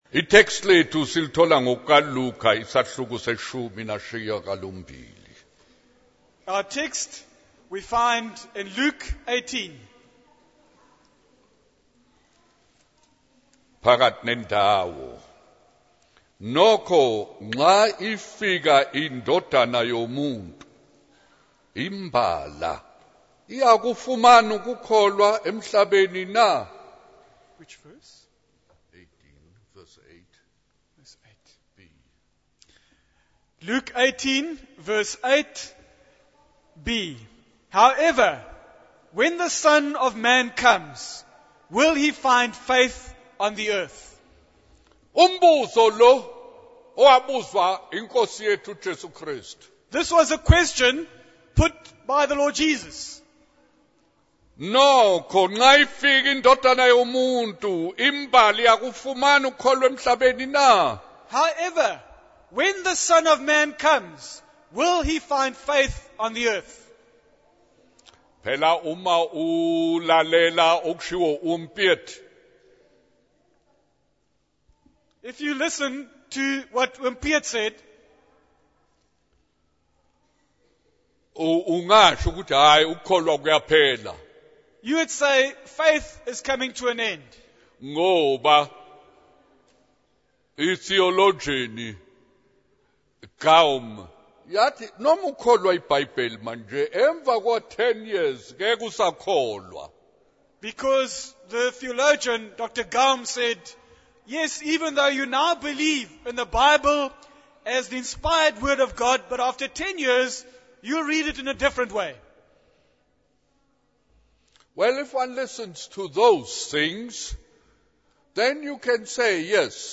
In this sermon, the preacher discusses the parable of the persistent widow and the unjust judge. He emphasizes the importance of having a faith that perseveres and does not lose heart.